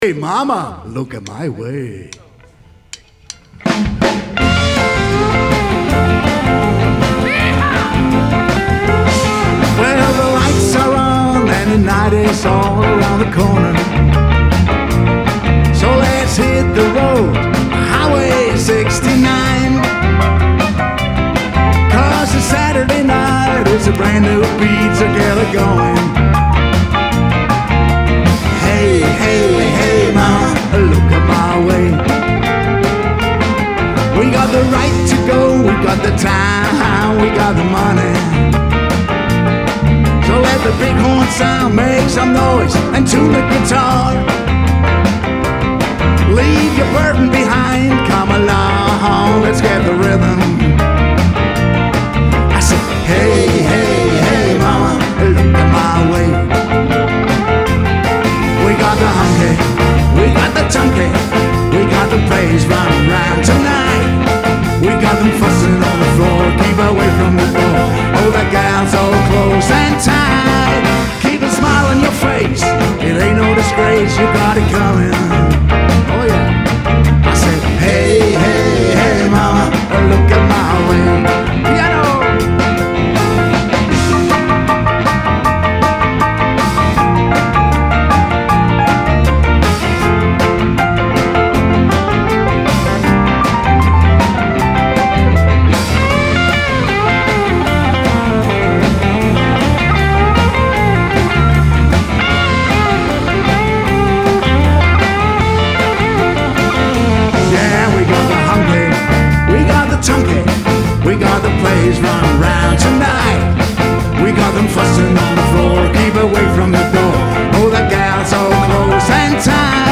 • Blues
• Country
• Singer/songwriter
• Nordisk americana